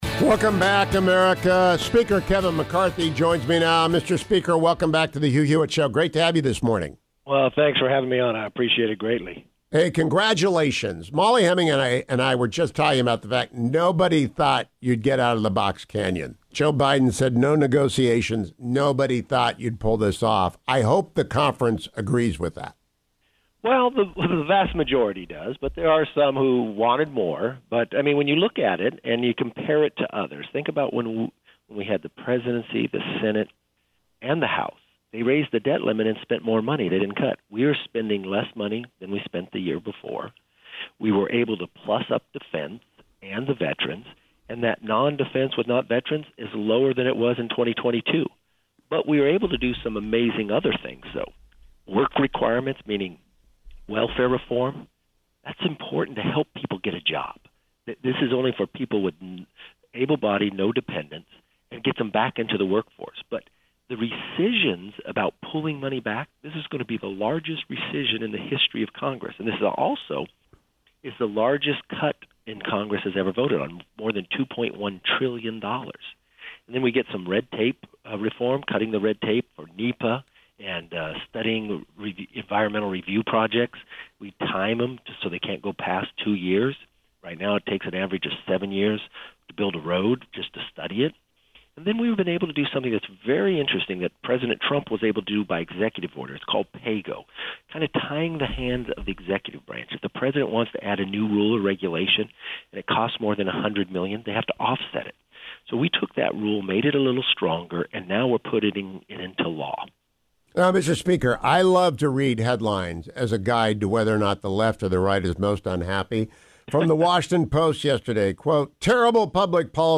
— House Speaker Kevin McCarthy (R-Calif.), in remarks on the Hugh Hewitt show on May 30